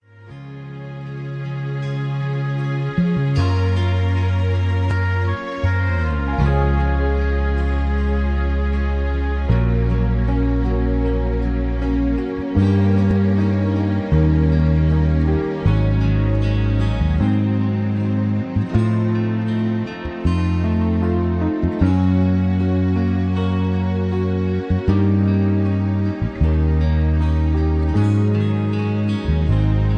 rock and roll
southern rock